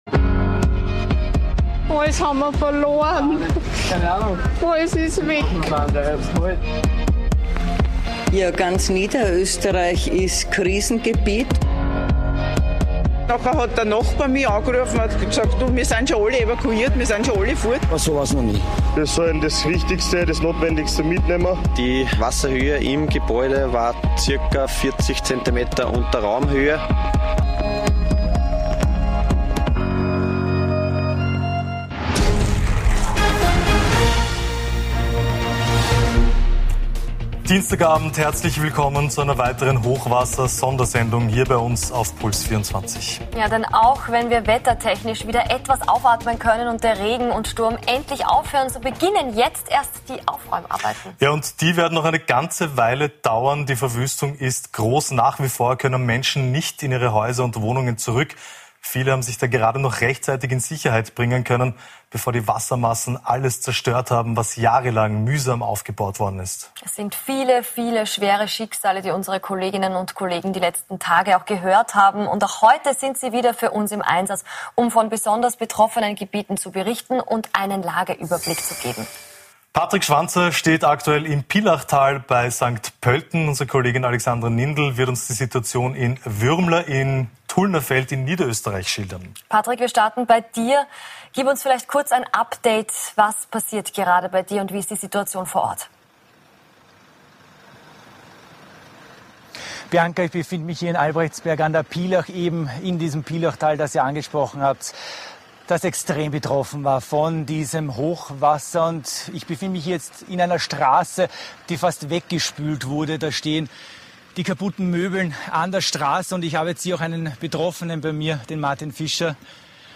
Wir schalten zu unseren Reporter:innen in den Katastrophengebieten, sprechen mit Betroffenen, den Held:innen in Feuerwehruniform, Meteorologen sowie Politik und Zivilschutzorganisationen.